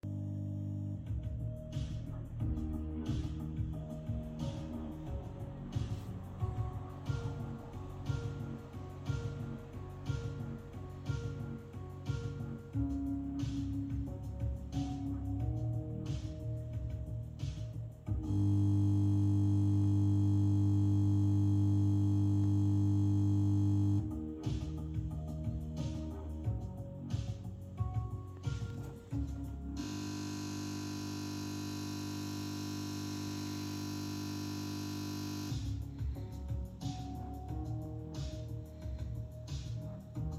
Zur Vollständigkeit hier noch die Tonspur vom letzten Crash. Wie man hört läuft es Teilweise, dann ein Loop, dann hängt es mal komplett.
(leider etwas leise) Anhänge Tonspur.mp3 Tonspur.mp3 632 KB